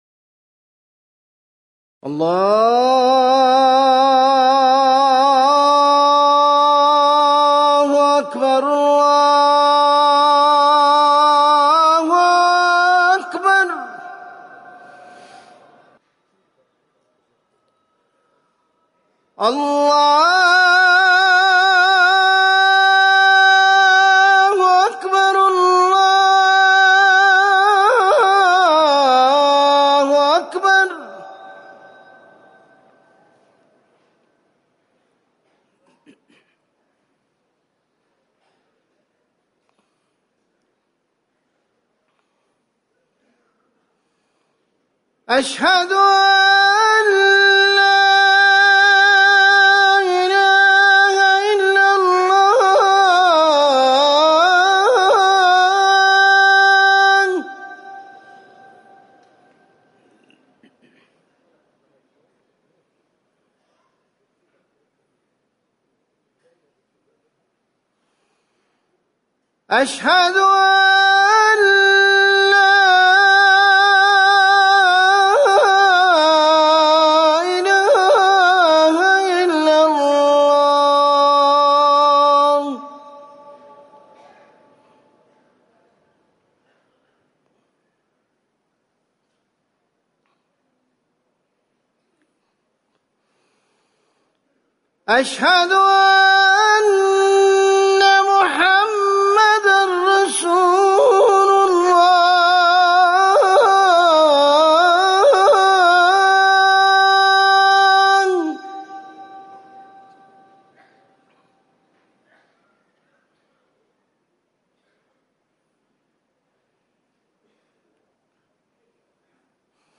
أذان العصر - الموقع الرسمي لرئاسة الشؤون الدينية بالمسجد النبوي والمسجد الحرام
تاريخ النشر ١٨ صفر ١٤٤١ هـ المكان: المسجد النبوي الشيخ